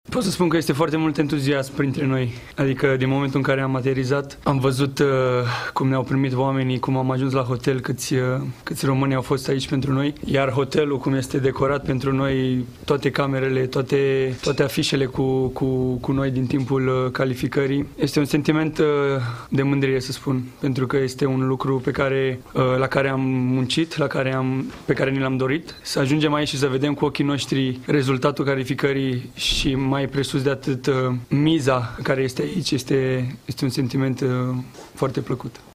Radu Drăgușin, fundaș central la Tottenham în Premier League, a făcut primele declarații după ce echipa națională a ajuns în Germania și a fost întâmpinată de mulți români: